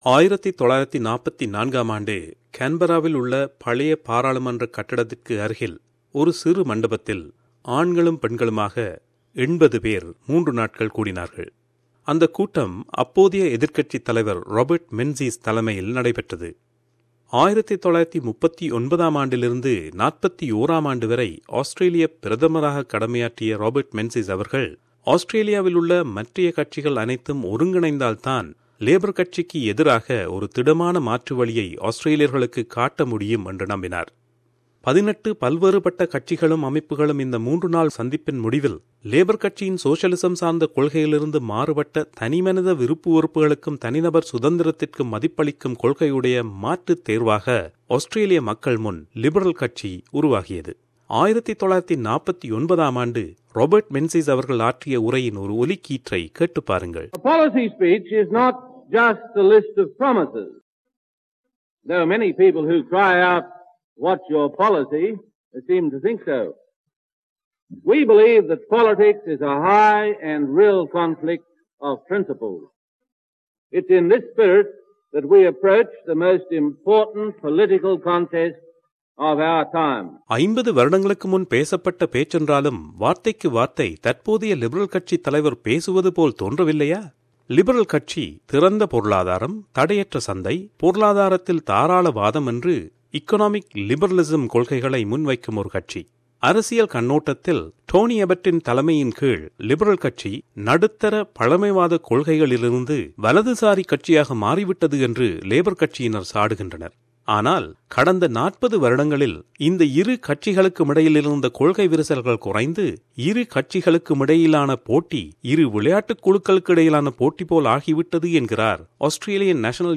ஒரு விவரணத்தைத் தமிழில் தருகிறார்